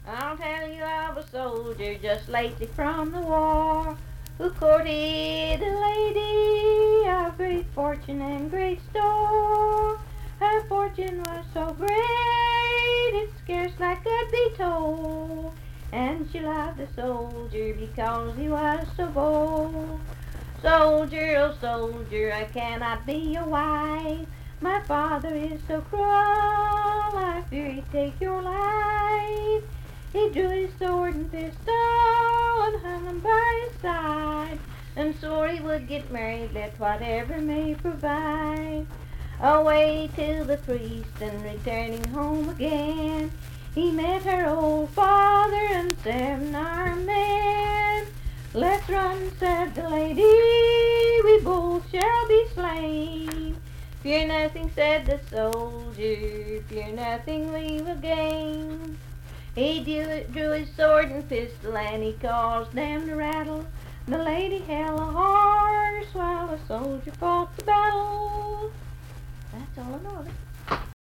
Unaccompanied vocal music
Verse-refrain 4(4-8).
Voice (sung)